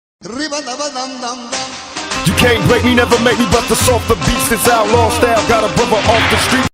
Play, download and share riba original sound button!!!!
riba-daba-dam-dam-ses-efekti-hd.mp3